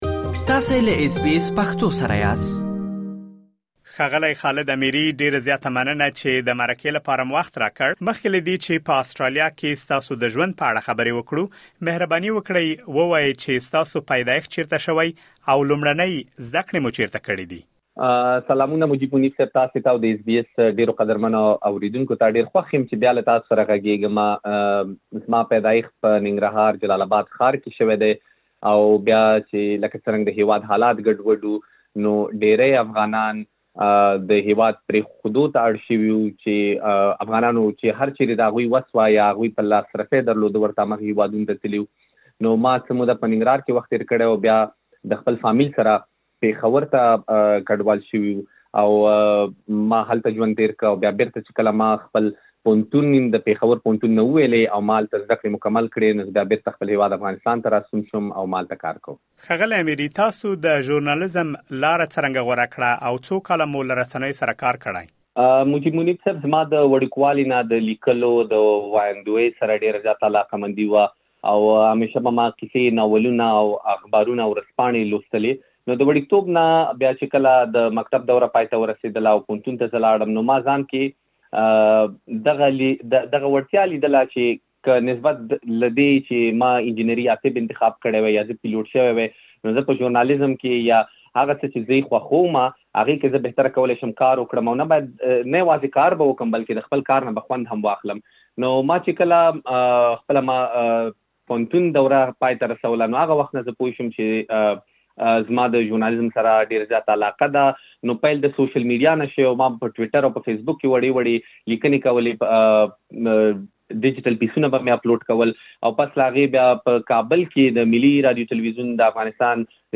مهرباني وکړئ لا ډېر معلومات په ترسره شوې مرکې کې واورئ.